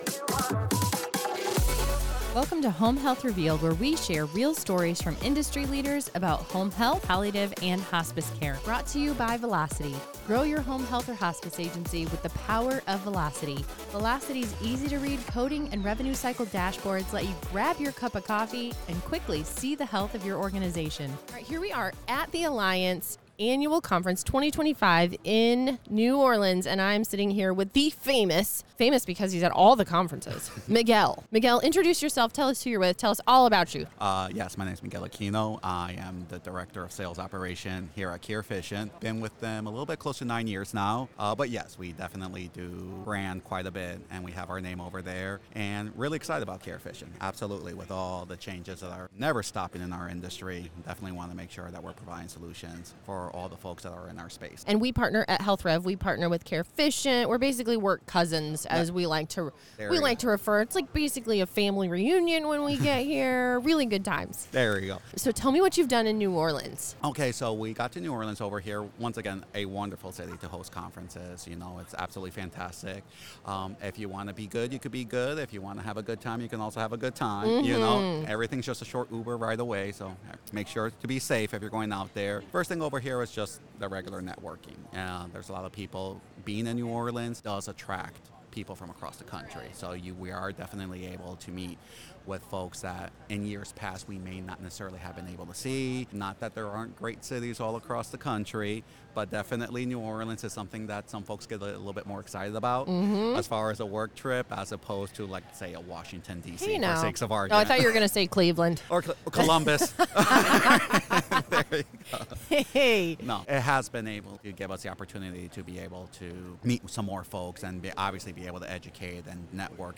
This Alliance conference episode brings big insights and even bigger New Orleans energy.